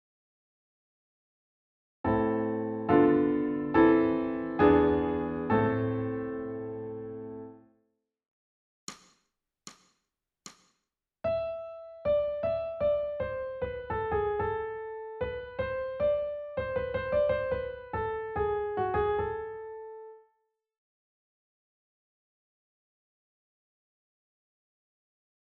ソルフェージュ 聴音: 1-iii-06